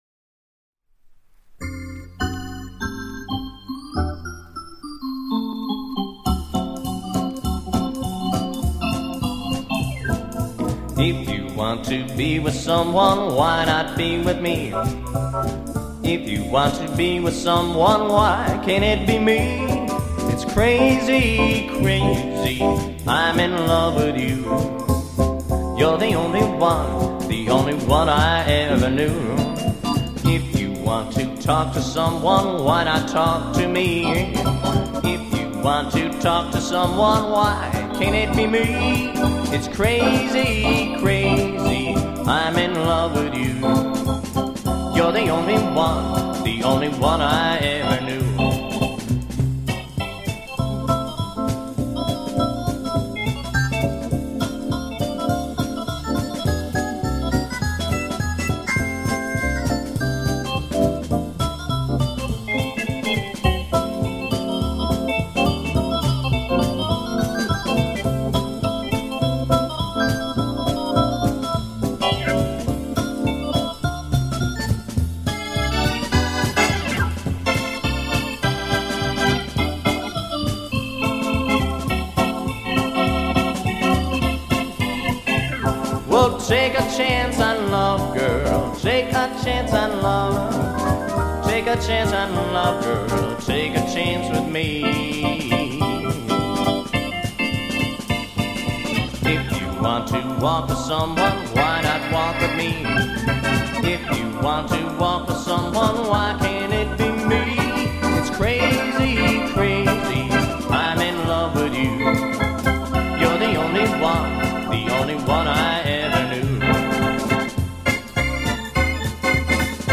De vreemde muziek